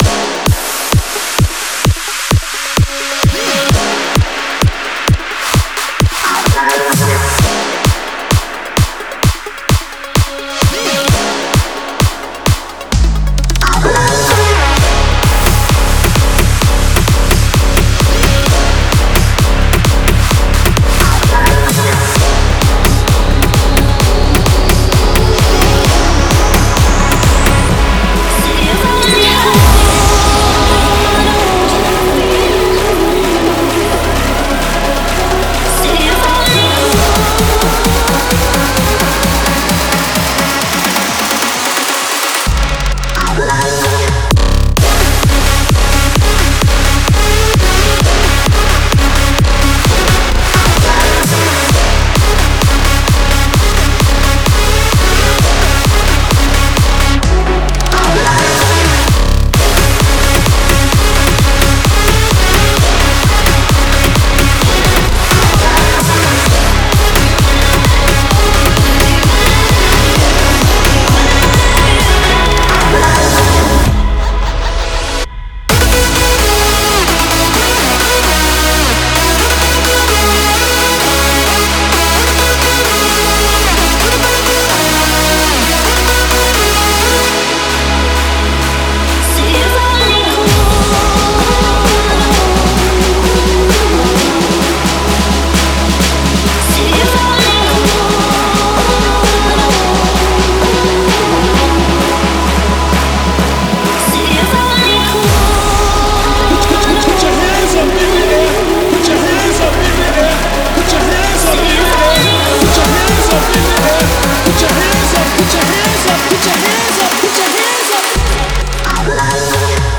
试听文件为低音质，下载后为无水印高音质文件 M币 15 超级会员 M币 8 购买下载 您当前未登录！